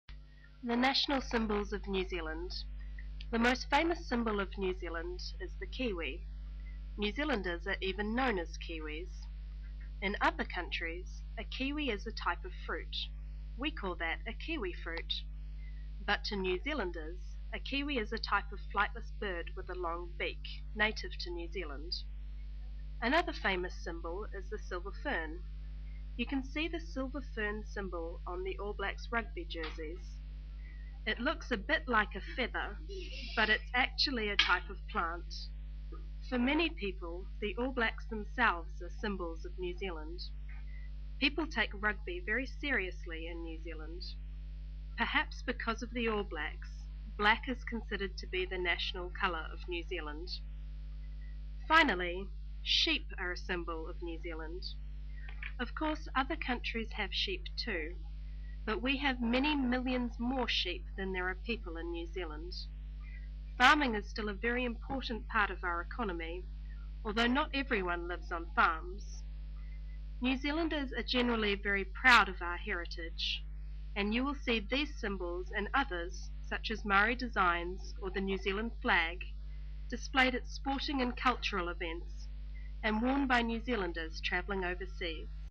Nouvelle Zélande